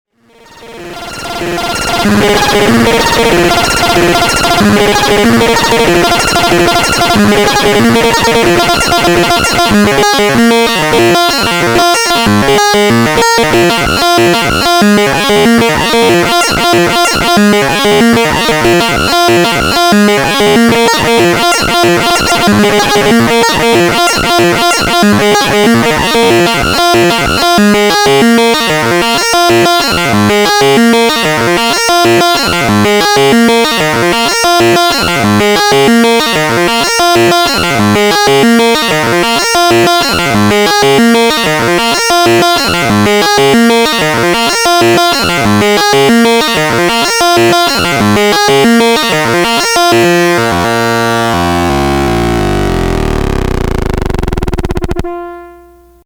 sample two: one oscillator audio sequence on the input. internal feedback controlled with cv input.